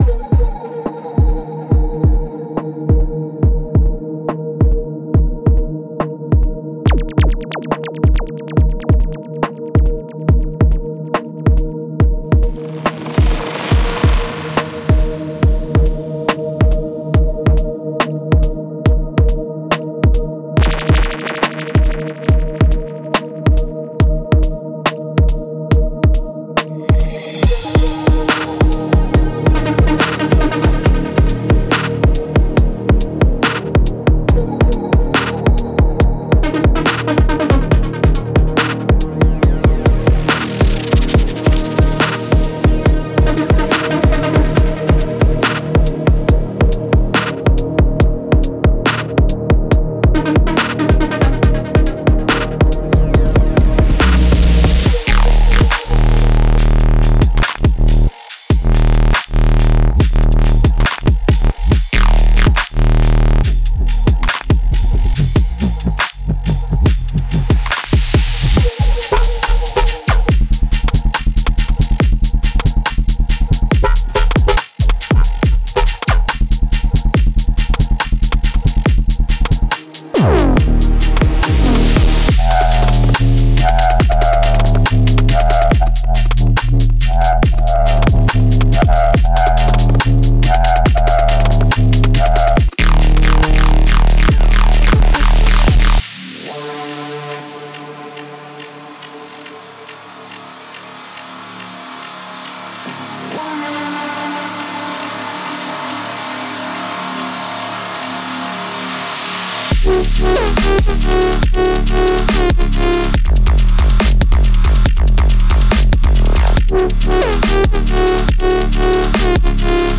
采样包提供紧实鼓组、幽暗贝斯、精简打击乐与氛围感合成器，如同深海回声般在混音中缓缓流动。
效果器单采样为作品带来潮汐般的动态变化，包含扫频过渡音效、冲击音效、环境纹理音效，以及类似声呐的激光与警笛音色。
包内还包含合成器循环，涵盖氛围铺底、氛围感主奏、律动琶音，以及和弦与弹拨音色，用幽暗的海洋色调为混音增添层次。
所有元素均用于营造深海般的张力、空间感与神秘感，无论是制作深邃氛围段落还是厚重 Halftime 律动都十分适用。